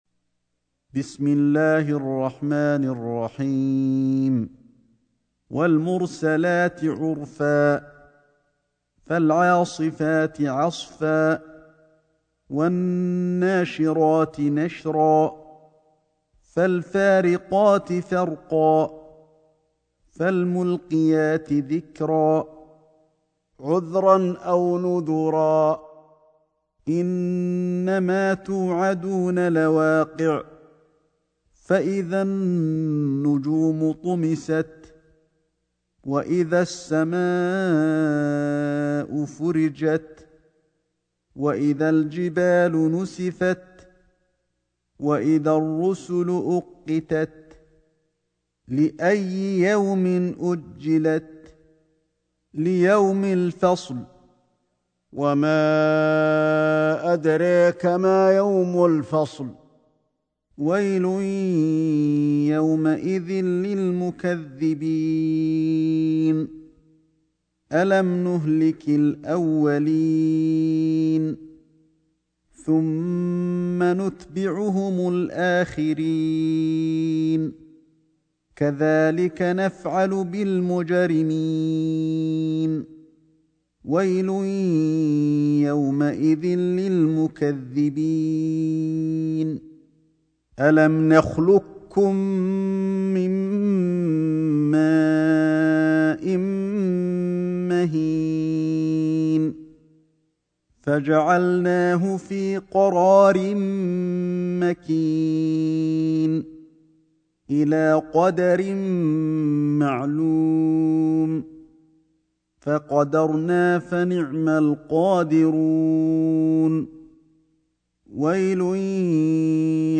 سورة المرسلات > مصحف الشيخ علي الحذيفي ( رواية شعبة عن عاصم ) > المصحف - تلاوات الحرمين